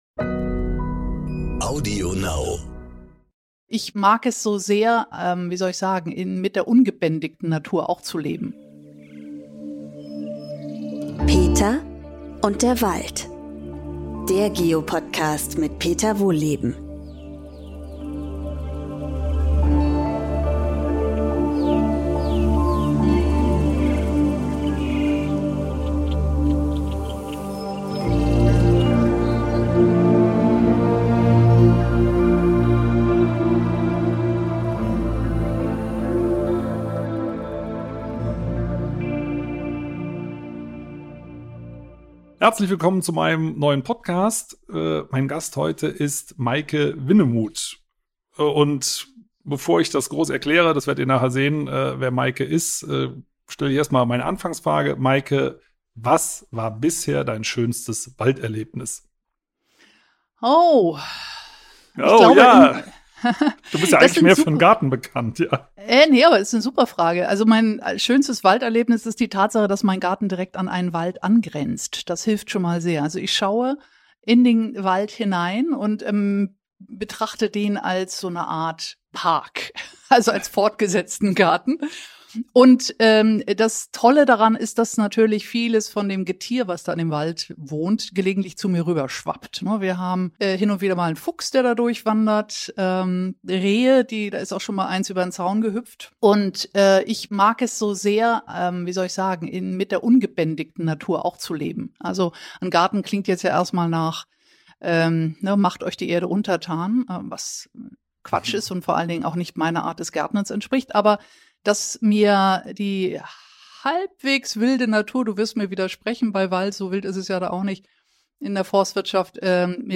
Ist Selbstversorung ein Fulltime Job?In dieser Folge trifft Peter Wohlleben die experimentierfreudige Journalistin, Autorin und jetzt auch Gärtnerin Meike Winnemuth. Beide sprechen über die Freuden des Gärtnerns, was man dem Garten beibringen kann, er einem aber auch selbst beibringt, und warum Pflanzen eigentlich kleine Haustiere sind.